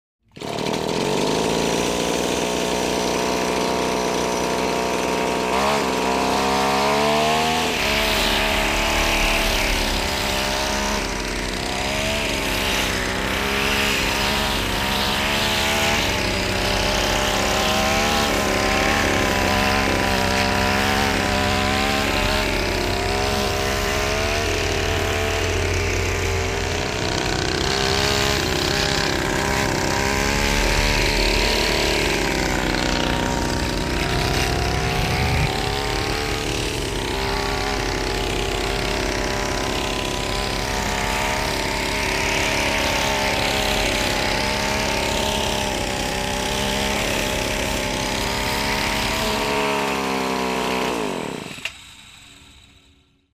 Звуки детских игрушек
Игрушечный самолет взлетает, парит и глохнет